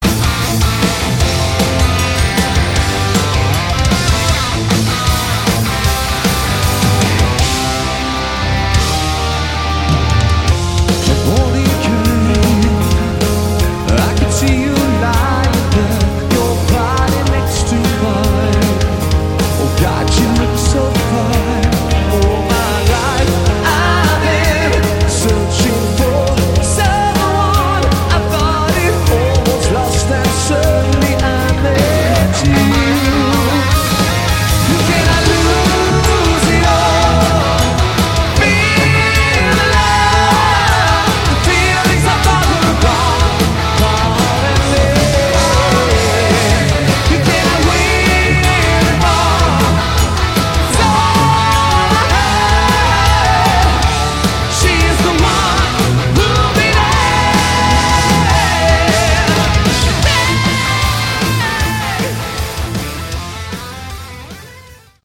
Category: Prog Rock
vocals
guitars
bass
drums
keyboards